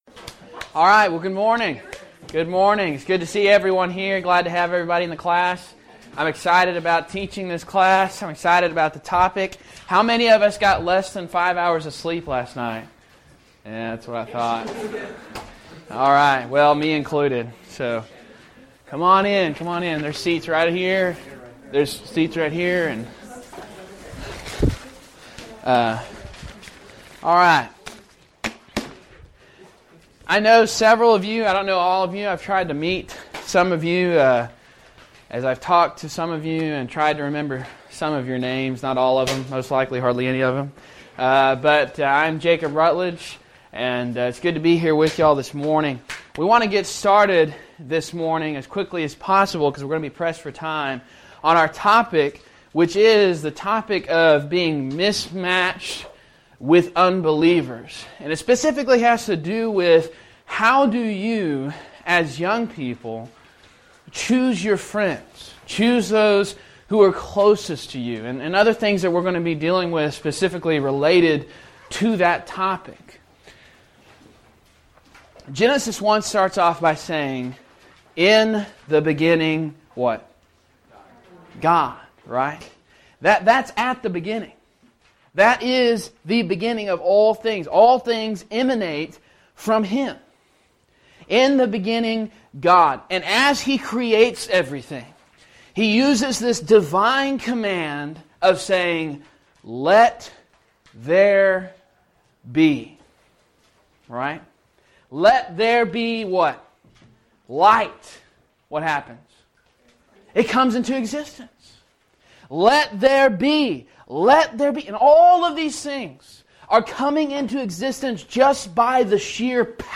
Event: Discipleship University 2012
Youth Sessions
If you would like to order audio or video copies of this lecture